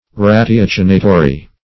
Meaning of ratiocinatory. ratiocinatory synonyms, pronunciation, spelling and more from Free Dictionary.
Search Result for " ratiocinatory" : The Collaborative International Dictionary of English v.0.48: Ratiocinatory \Ra`ti*oc"i*na*to*ry\ (r[a^]sh`[i^]*[o^]s"[i^]*n[.a]*t[-o]*r[y^]), a. Ratiocinative.